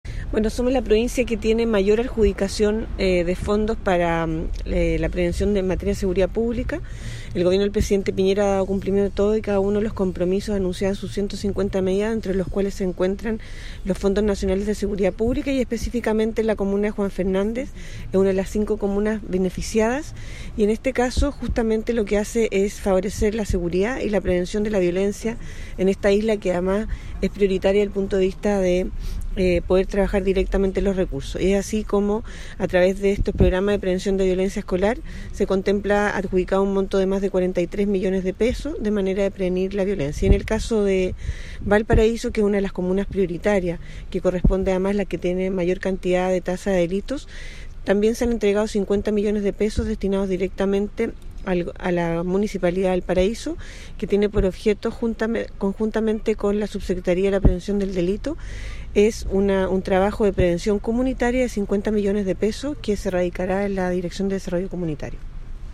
CUÑA-Gobernadora-Valpo-x-Fondo-Nacional-Seguridad-Pública-Ganadores-2019.mp3